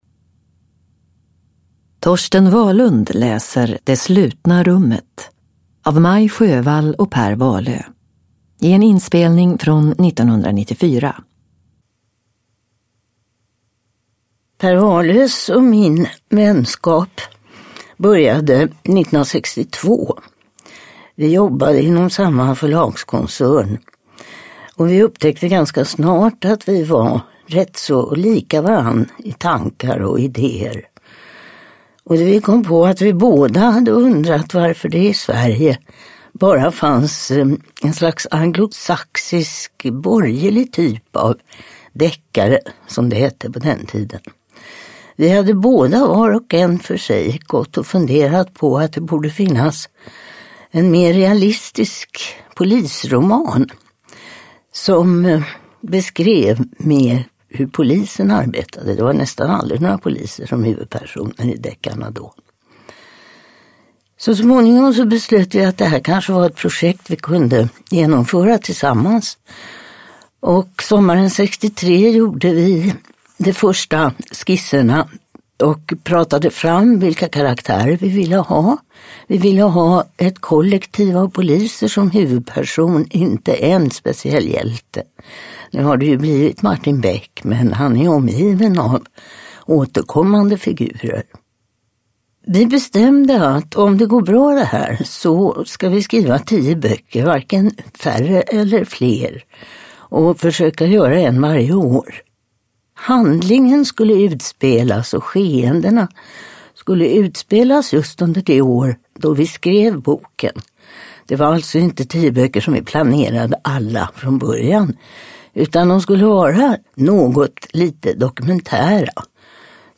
Det slutna rummet (ljudbok) av Sjöwall Wahlöö